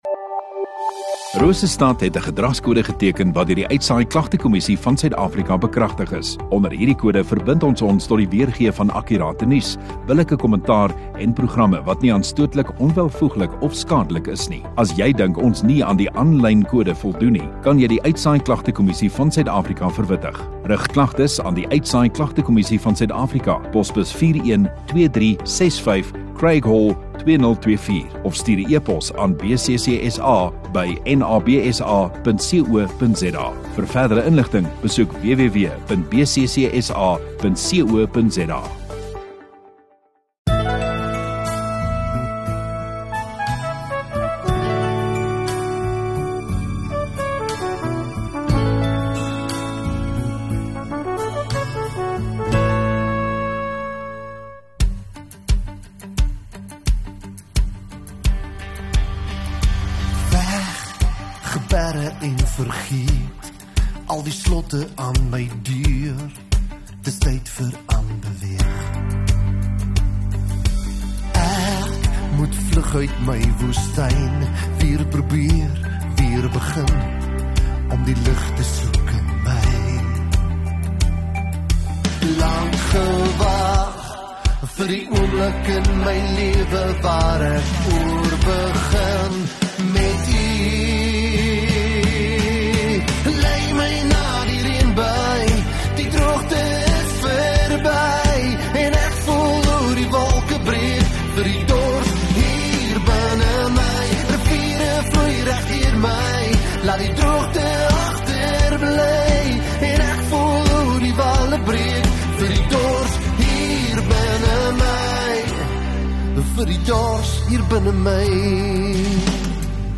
22 Oct Sondagoggend Erediens